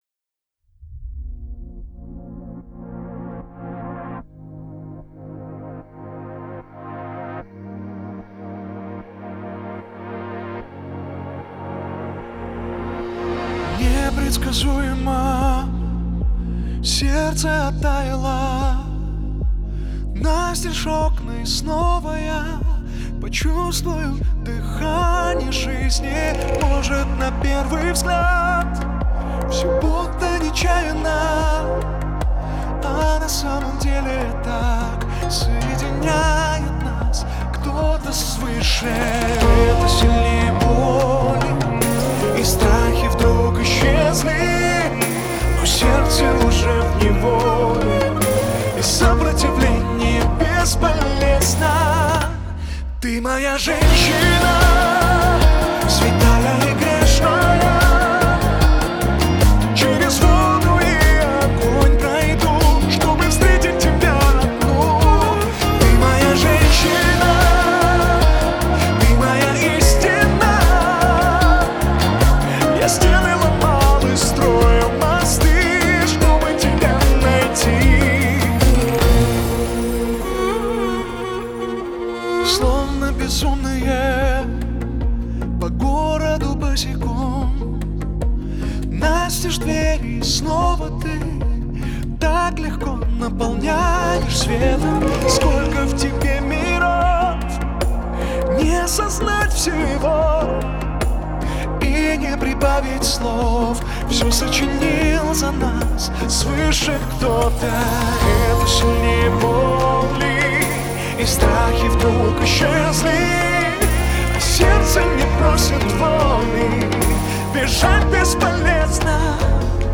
романтичная поп-баллада